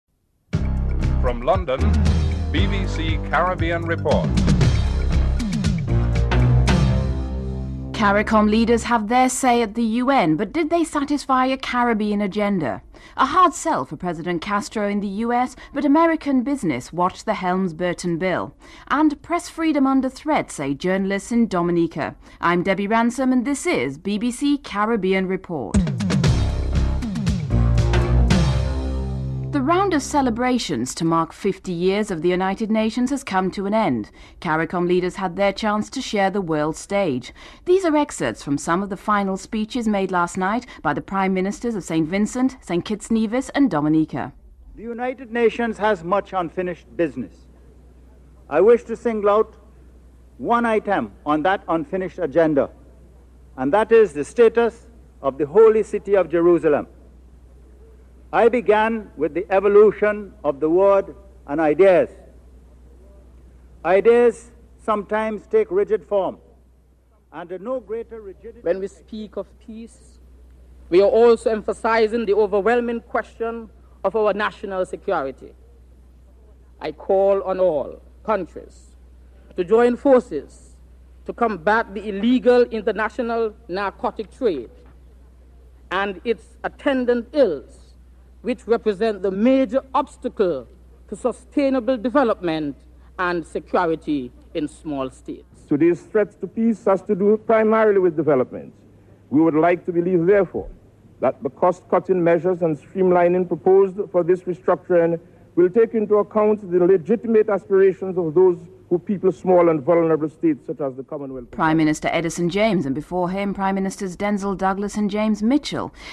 In this report the excerpts of the final speeches made by the Prime Ministers of St. Vincent, St. Kitts/Nevis and Dominica at the 50th Anniversary ceremony of the UN are highlighted.
Dr. Sahadeo Basdeo, former Foreign Minister of Trinidad and Tobago criticises Caribbean leaders for this missed opportunity.